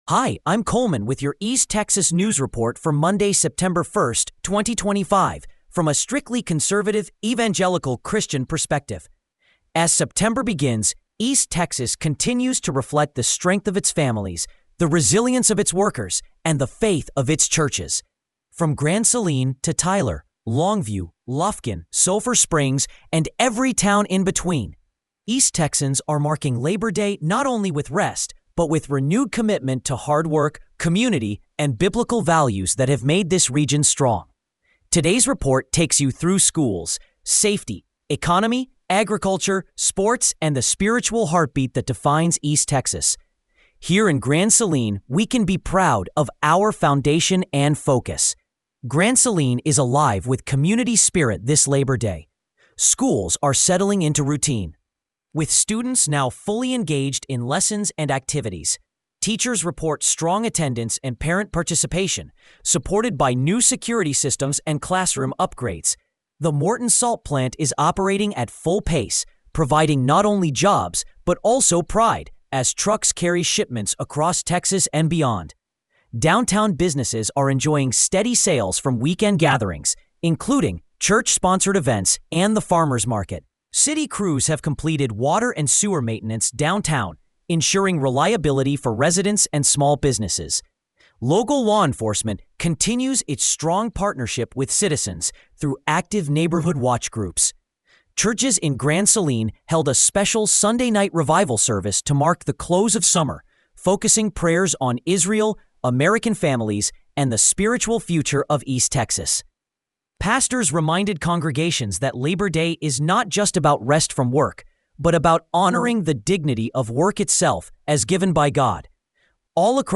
East Texas News Report for Monday, September 1, 2025